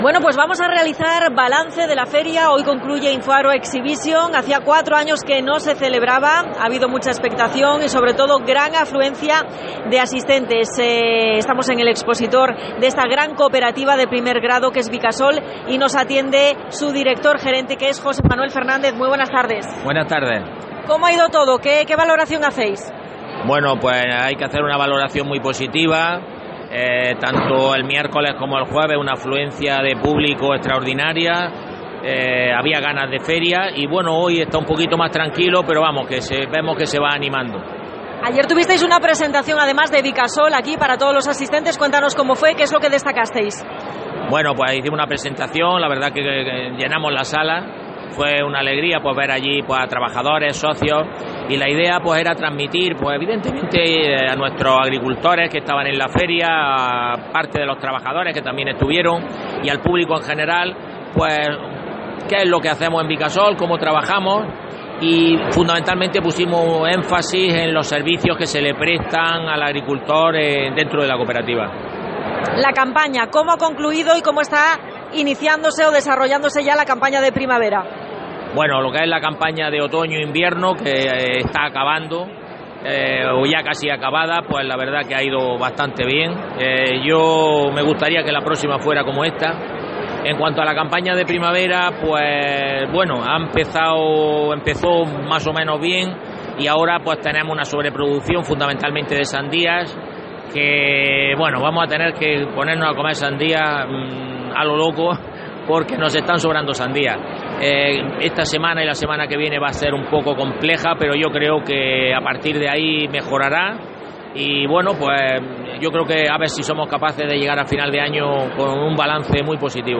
Entrevista en Infoagro